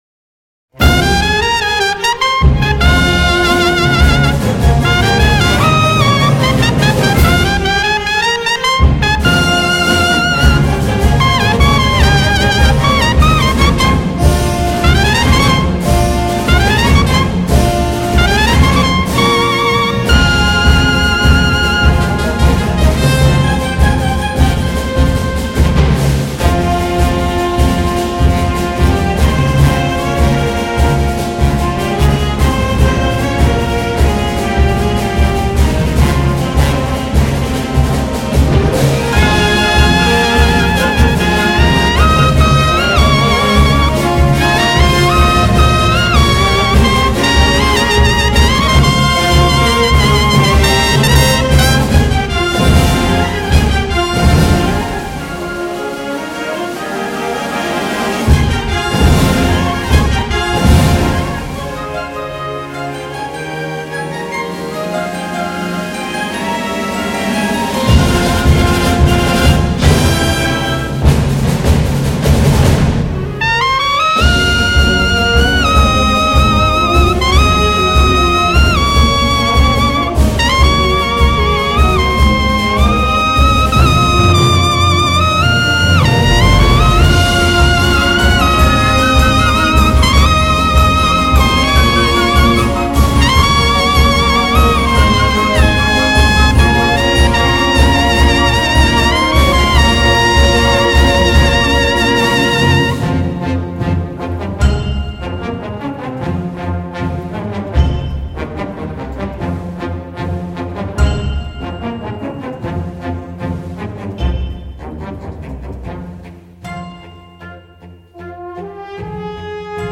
corneta española